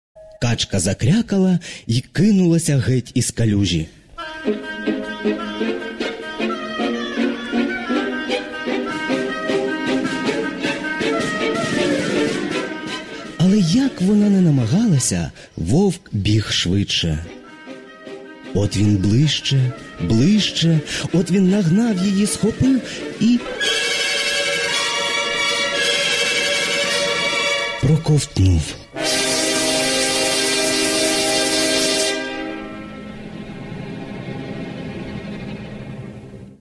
Симфонічна казка «Петрик і Вовк» Теми Кішки, Дідуся, Вовка, Мисливців